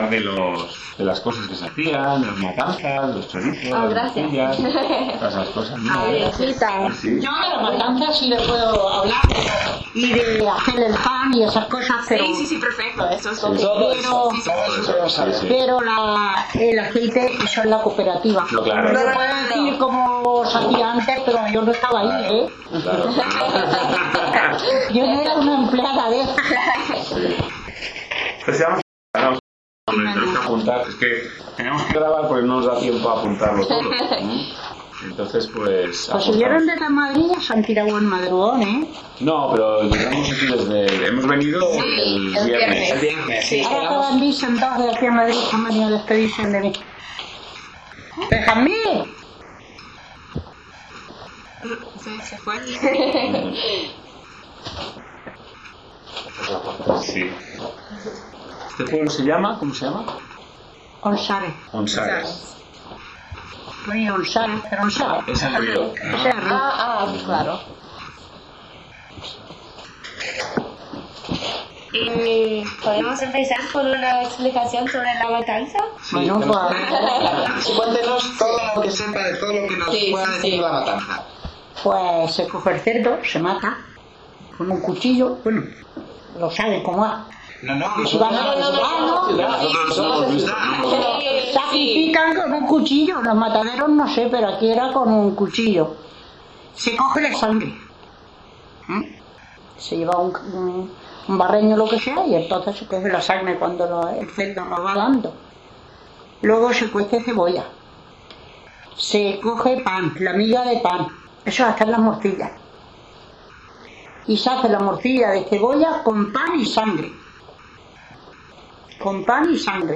Locality Onsares (Villarrodrigo)